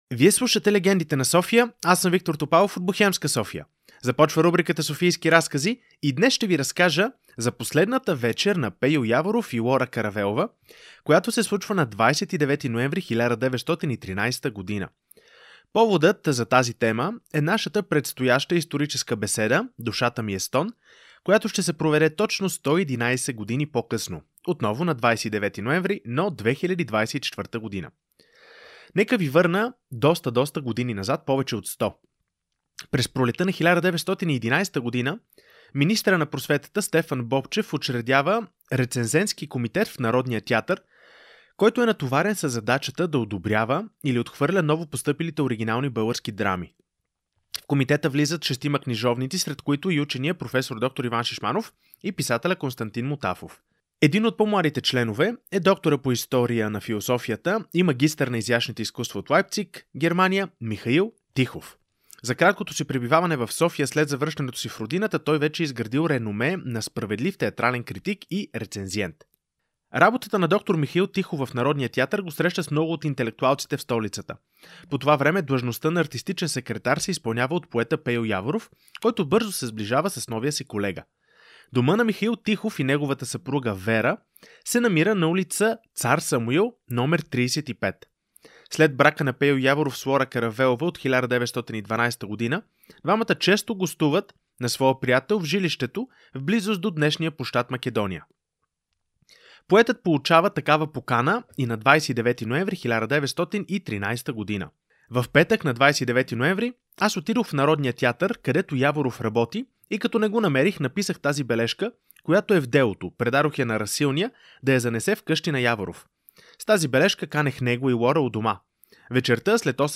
"Душата ми е стон" е темата на поредната беседа на Бохемска София с герои Яворов и Лора в Софийски разкази, част от Легендите на София